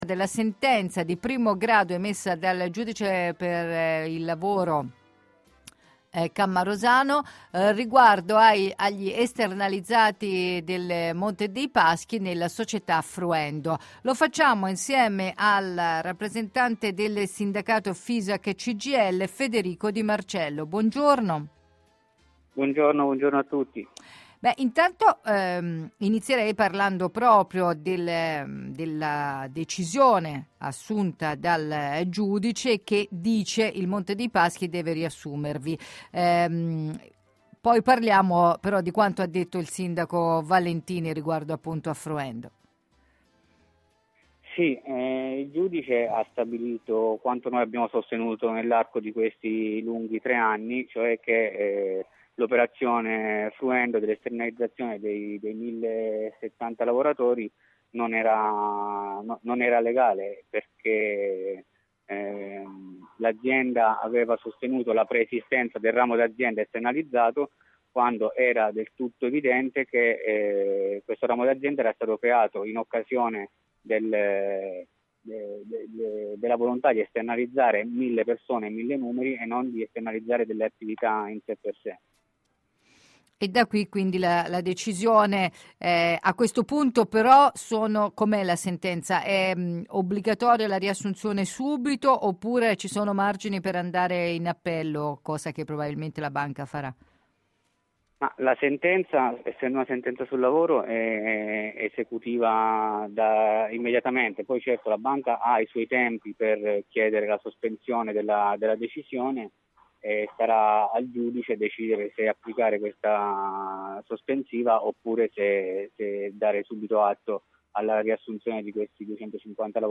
Sentenza esternalizzati Mps nella società Fruendo, in diretta